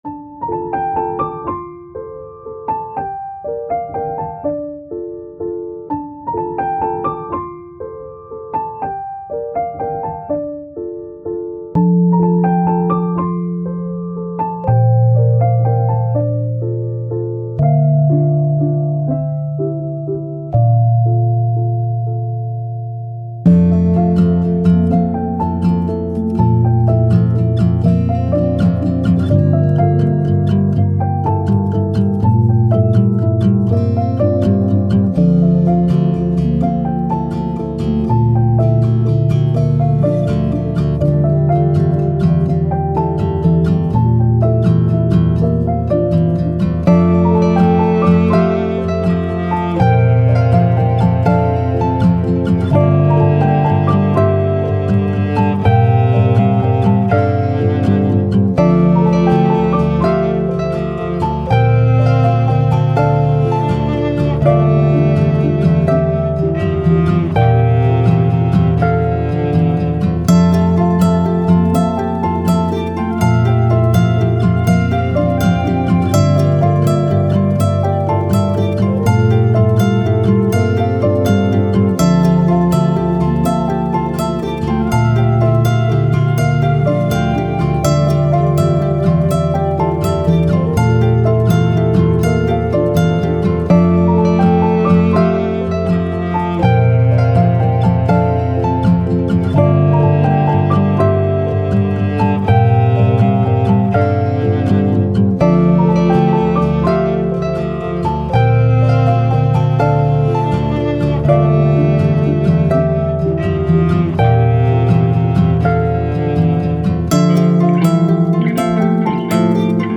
Soundtrack, Ambient, Piano, Emotive, Acoustic, Endings